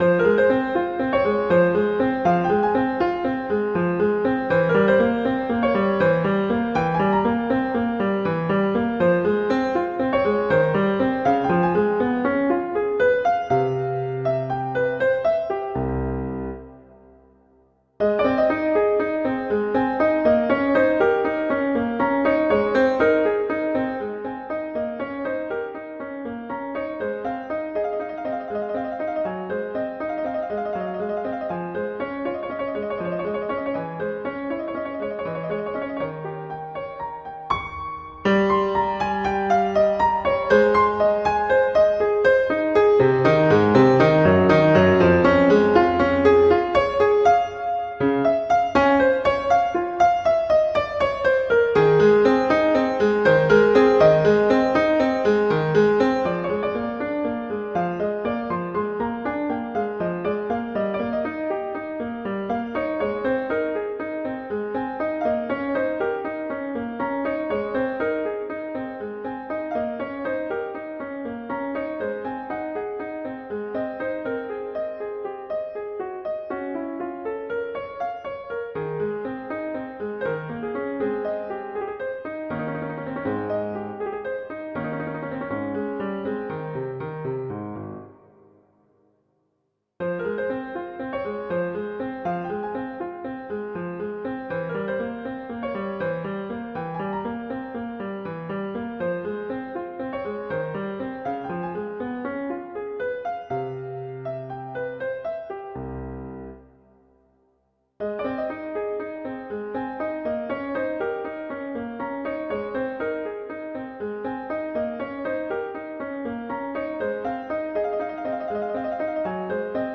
piano solo
classical
Adagio